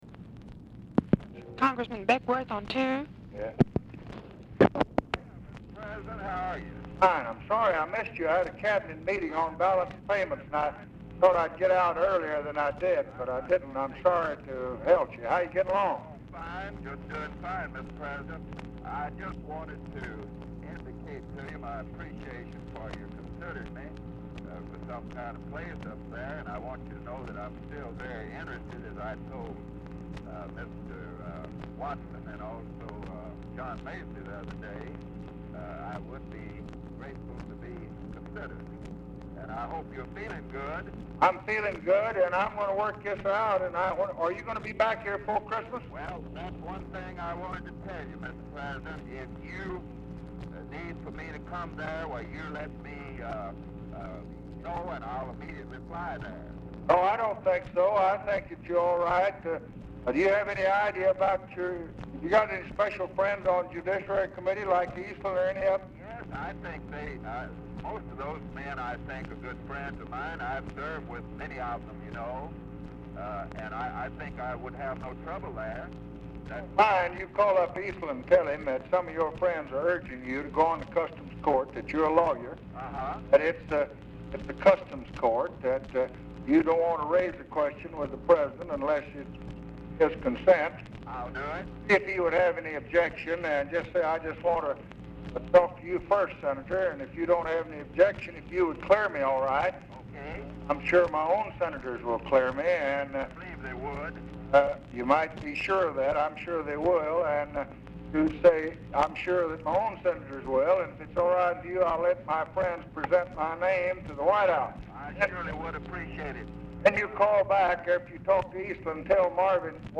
Title Telephone conversation # 11124, sound recording, LBJ and LINDLEY BECKWORTH, 12/10/1966, 5:37PM Archivist General Note "(GLADEWATER, TEX.
BECKWORTH IS DIFFICULT TO HEAR
Format Dictation belt